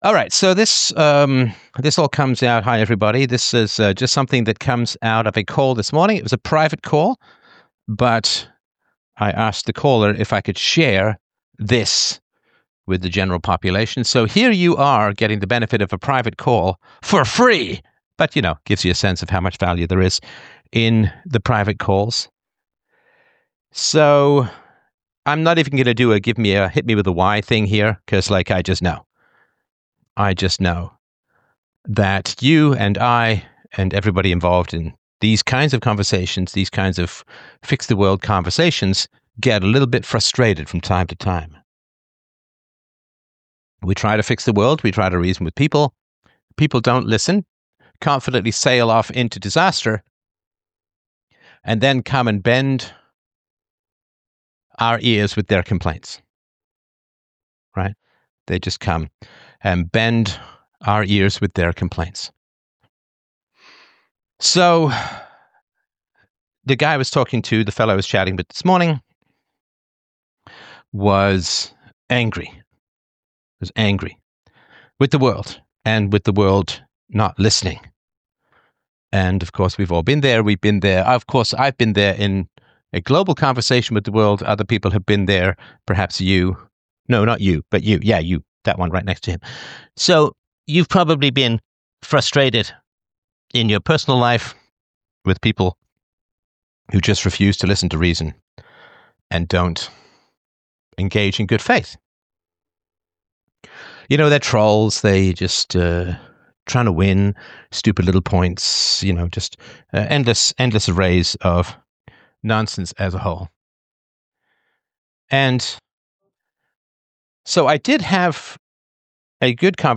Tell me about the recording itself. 1 5970 How to Control Your Happiness! CALL IN LIVESTREAM 1:20:57